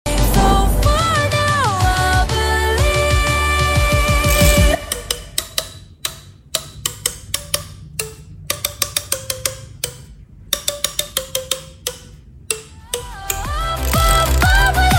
Golden - Musical Fidget #3dprinting